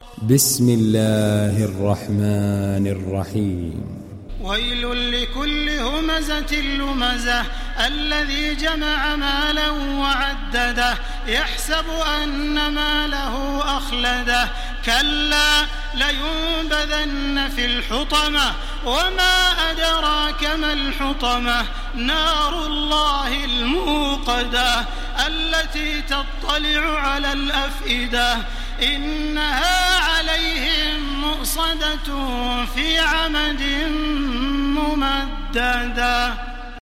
Humeze Suresi İndir mp3 Taraweeh Makkah 1430 Riwayat Hafs an Asim, Kurani indirin ve mp3 tam doğrudan bağlantılar dinle
İndir Humeze Suresi Taraweeh Makkah 1430